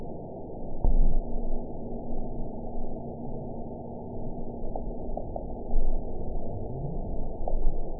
event 921747 date 12/18/24 time 19:12:20 GMT (4 months, 3 weeks ago) score 8.63 location TSS-AB03 detected by nrw target species NRW annotations +NRW Spectrogram: Frequency (kHz) vs. Time (s) audio not available .wav